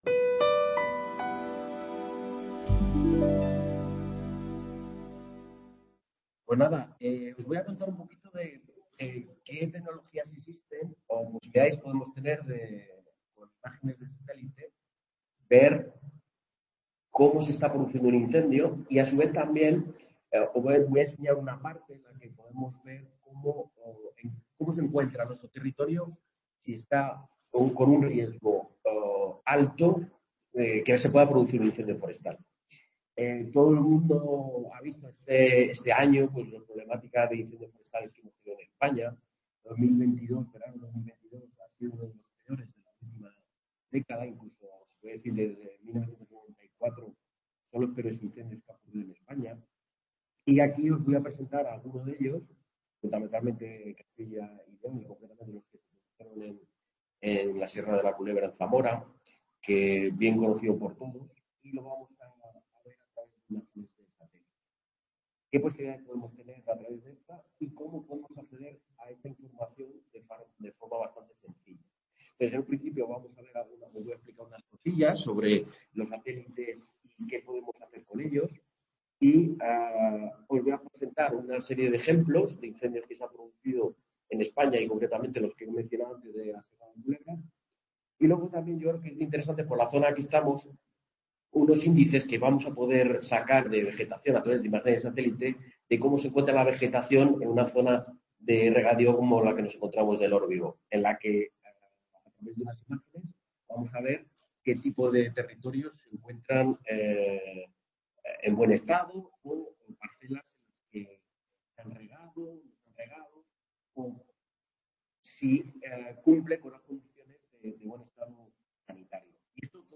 Aula de PONFERRADA: Conferencias y talleres